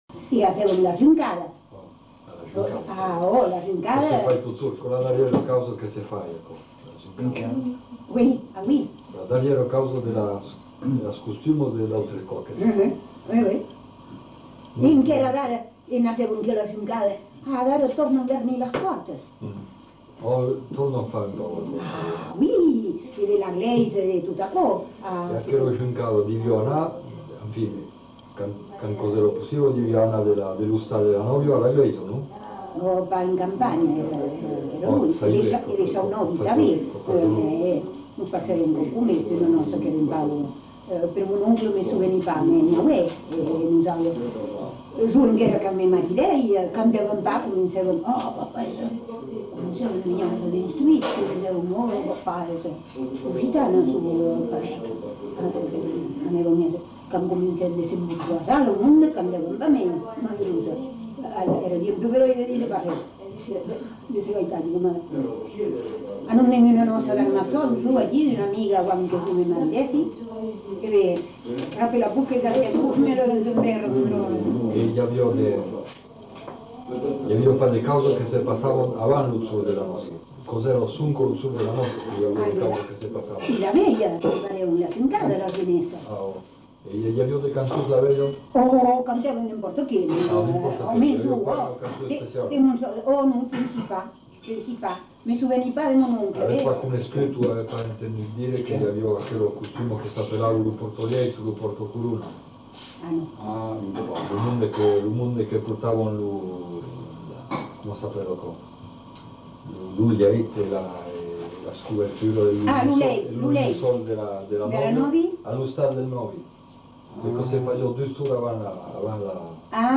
Lieu : Tonneins
Genre : témoignage thématique